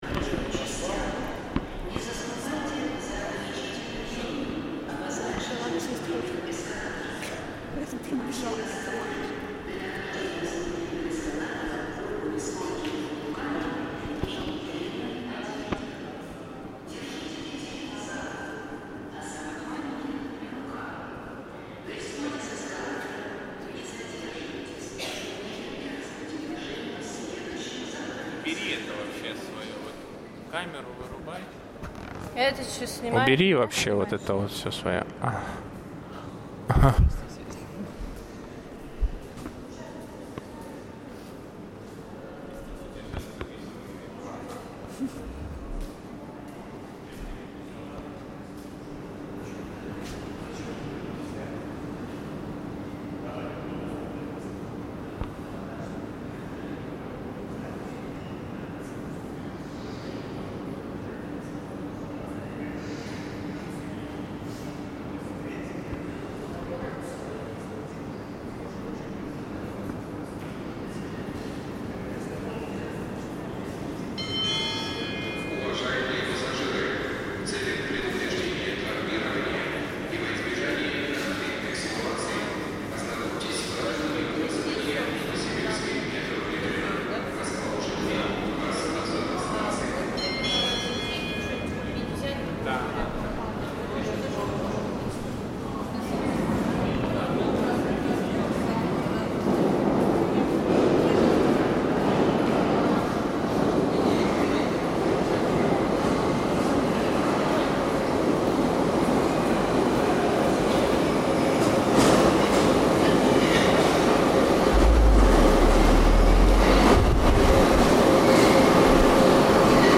Recording from the Novosibirsk metro, Russia.